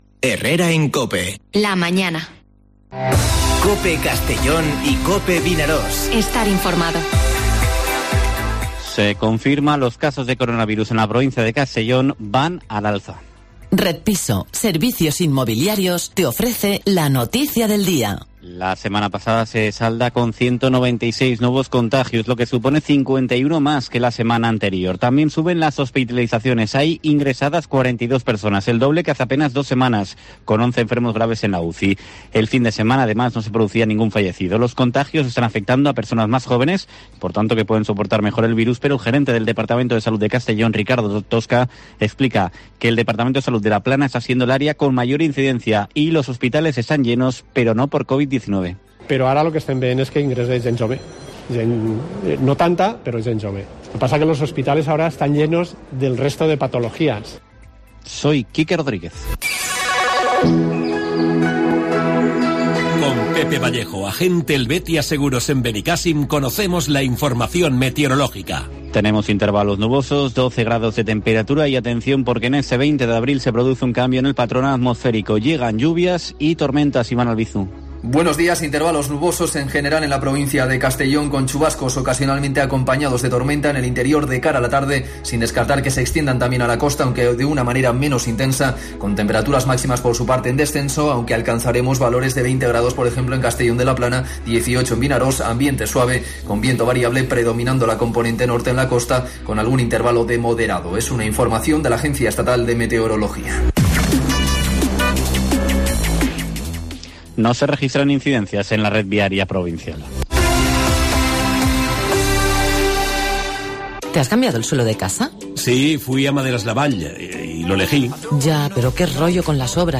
Informativo Herrera en COPE en la provincia de Castellón (20/04/2021)